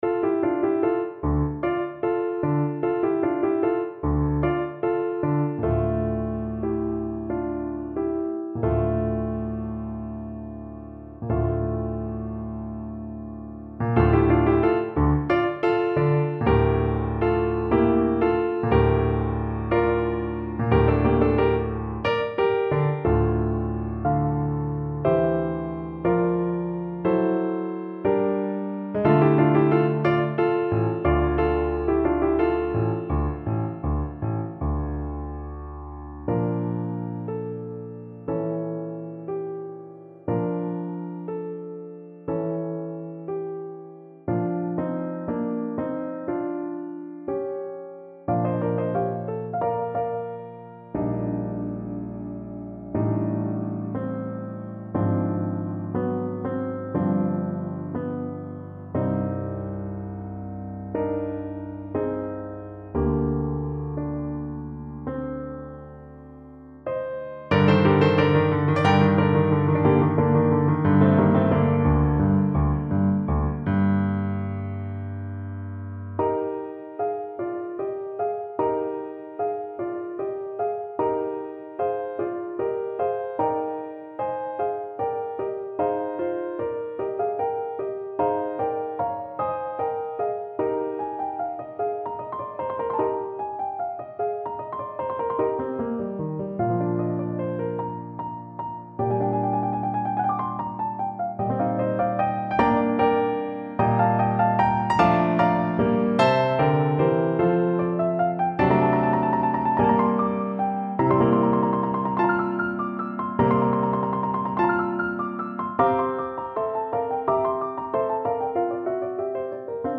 Voice
D minor (Sounding Pitch) (View more D minor Music for Voice )
= 69 = 100 Allegro (View more music marked Allegro)
4/8 (View more 4/8 Music)
B4-G6
Classical (View more Classical Voice Music)